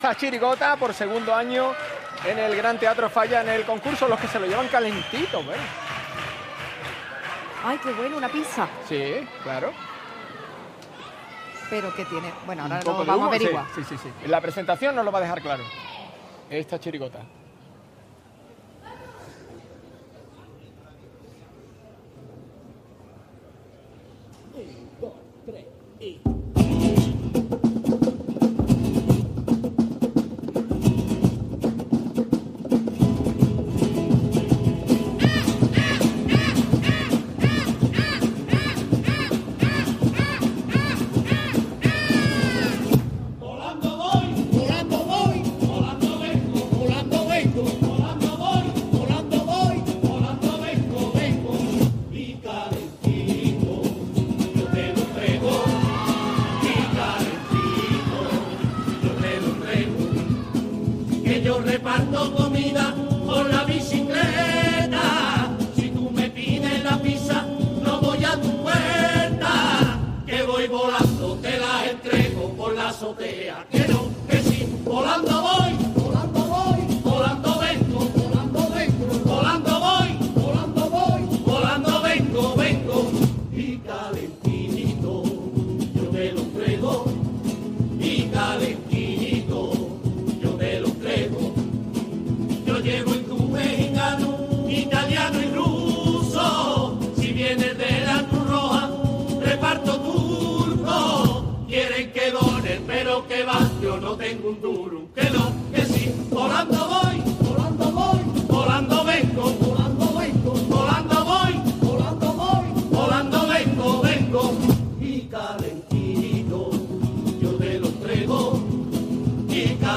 en la fase preliminares del COAC Carnaval de Cádiz 2026